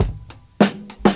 • 115 Bpm Fresh Breakbeat Sample F# Key.wav
Free drum loop sample - kick tuned to the F# note. Loudest frequency: 1430Hz
115-bpm-fresh-breakbeat-sample-f-sharp-key-DFy.wav